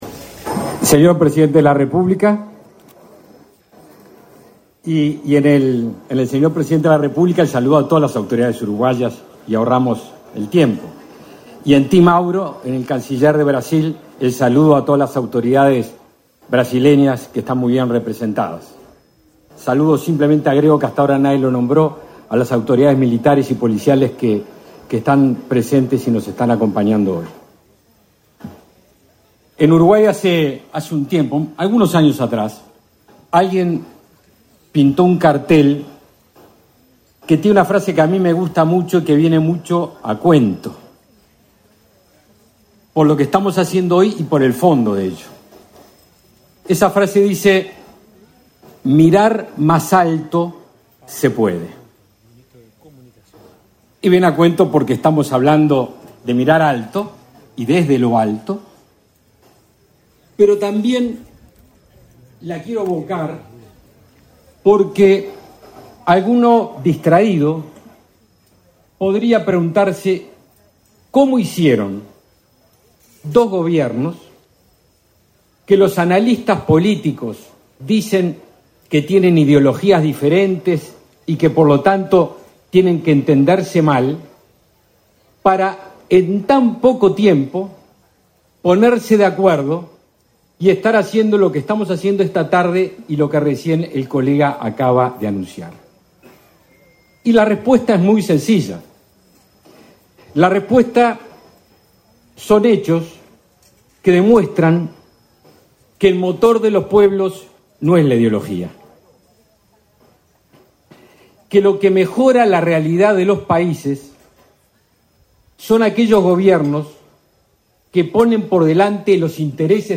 Palabras del ministro de Defensa Nacional, Javier García 11/12/2023 Compartir Facebook Twitter Copiar enlace WhatsApp LinkedIn Con la presencia del presidente de la República, Luis Lacalle Pou, se inauguró, este 11 de diciembre, el aeropuerto binacional de Rivera. En la oportunidad, el ministro de Defensa Nacional, Javier García, realizó declaraciones.